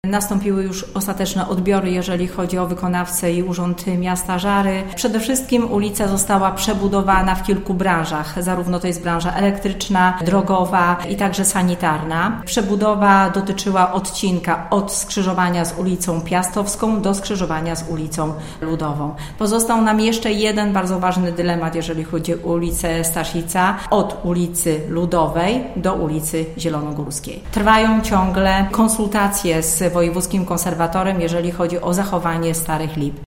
– Ulica Staszica jest jednak przejezdna, bezpieczna i dostępna dla pojazdów i pieszych – informuje Danuta Madej, burmistrz Żar.